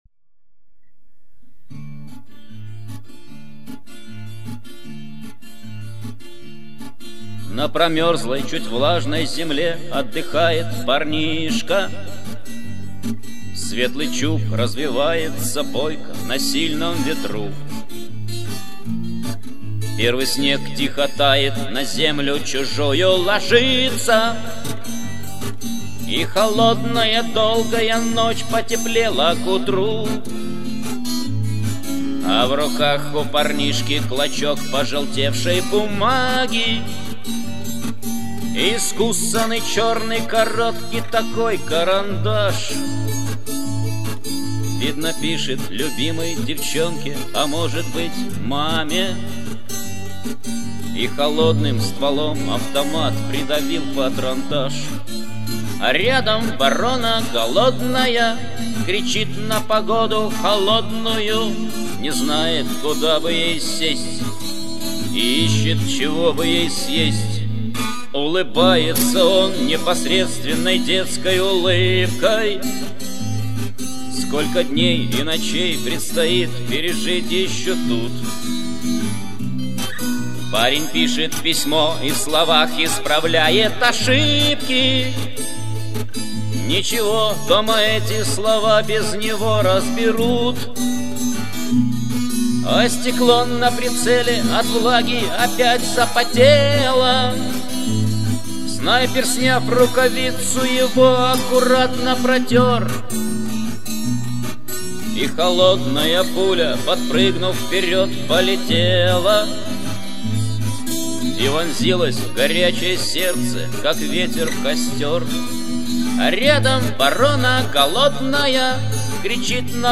Гитара / армейские